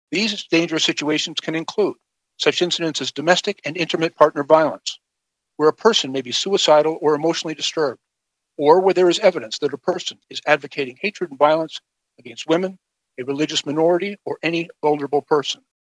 Public Safety Minister Bill Blair told a news conference the bill will also give police broader authority to take weapons away from people in what he describes as “dangerous situations.”